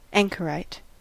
Ääntäminen
Ääntäminen US